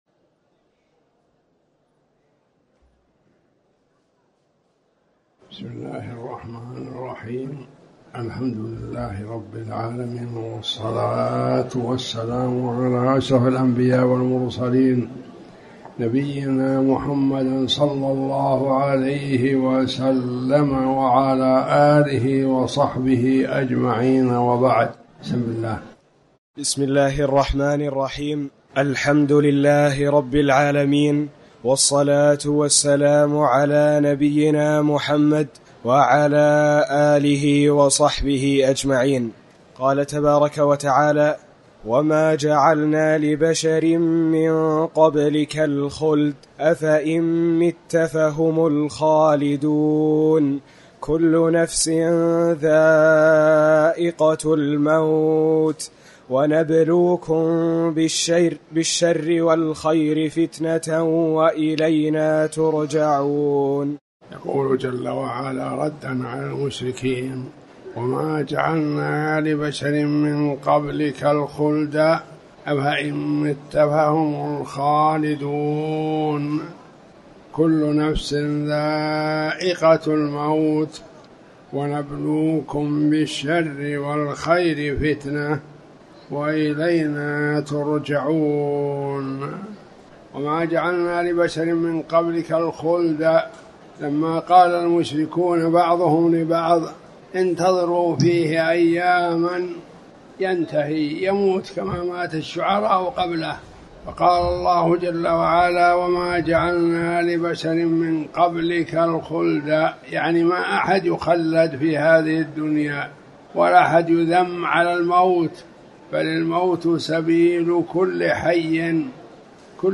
تاريخ النشر ٨ جمادى الآخرة ١٤٤٠ هـ المكان: المسجد الحرام الشيخ